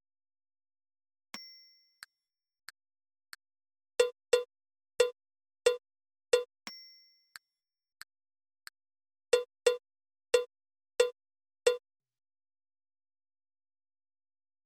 「リズムカード」正解のリズム音源
※リズム音源のテンポは♩＝90です。Moderato/モデラート(♩＝76～96)ほどのテンポとなります。
※はじめにカウントが流れます。
※リズムは2回流れます。